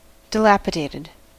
Ääntäminen
IPA : /dɪˈlæpɪdeɪtəd/